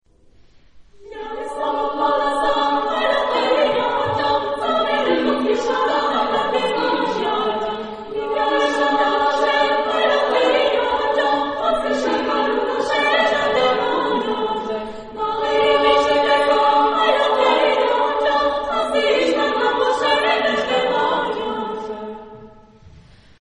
Género/Estilo/Forma: Canción ; baile ; Profano
Tipo de formación coral: SSAA  (4 voces Coro femenino )
Ref. discográfica: Internationaler Kammerchor Wettbewerb Marktoberdorf 2007